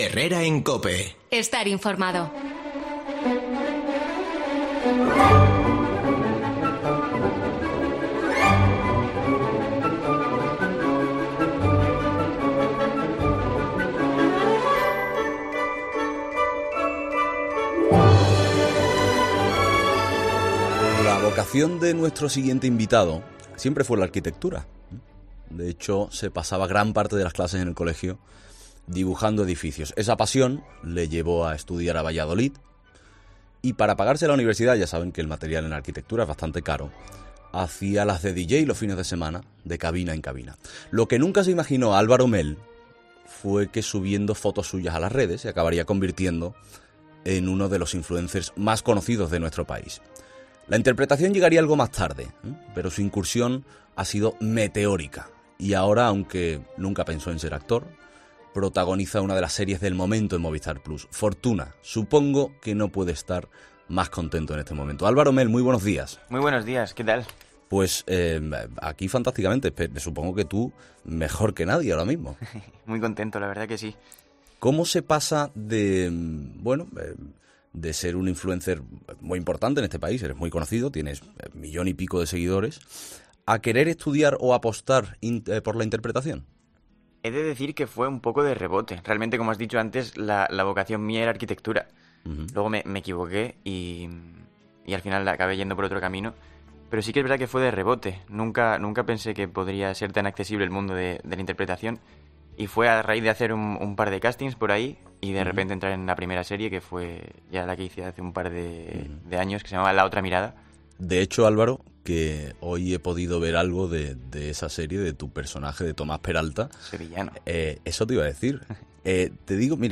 El actor nos habla sobre cómo fue su inicio en la actuación y su experiencia trabajando con el director Alejandro Amenábar
Esta mañana, en los micrófonos de "Herrera en COPE", nos ha visitado el actor, modelo e influencer, Álvaro Mel .